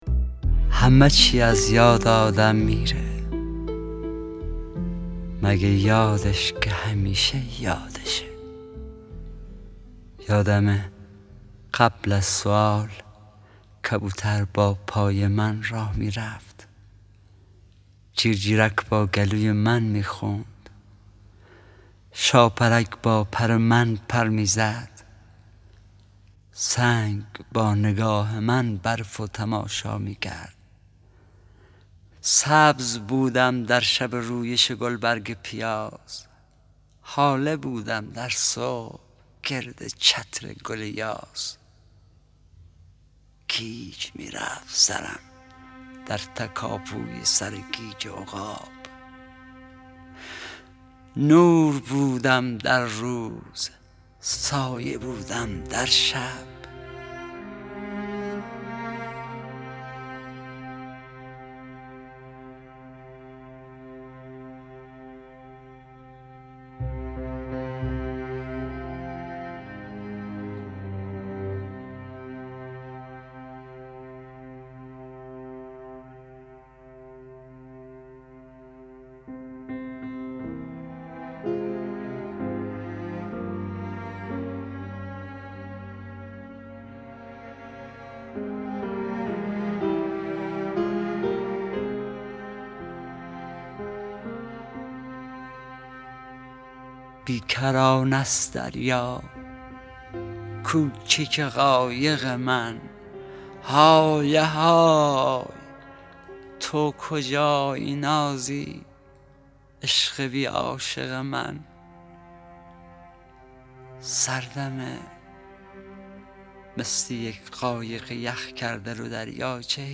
دانلود دکلمه همه چی از یاد آدم میره با صدای حسین پناهی
گوینده :   [حسین پناهی]